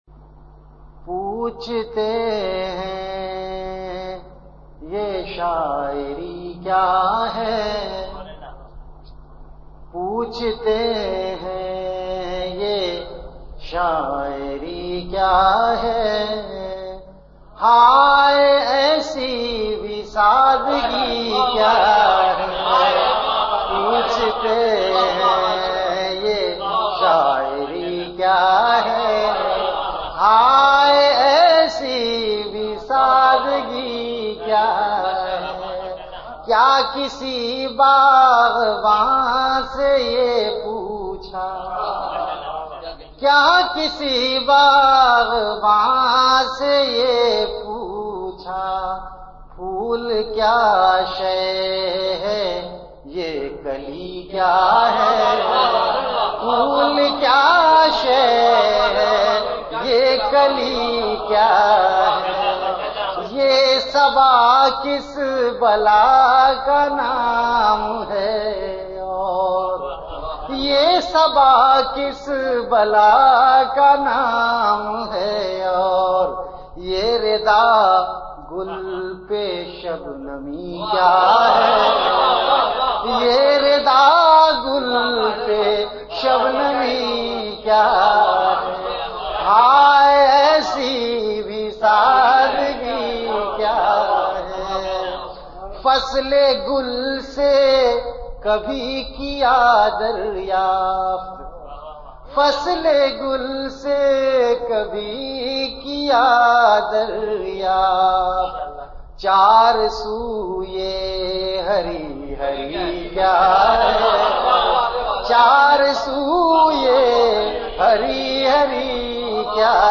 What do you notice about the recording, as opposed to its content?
VenueKhanqah Imdadia Ashrafia Event / TimeAfter Isha Prayer